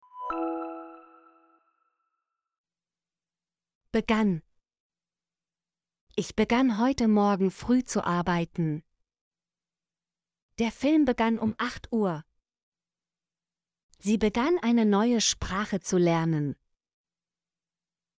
begann - Example Sentences & Pronunciation, German Frequency List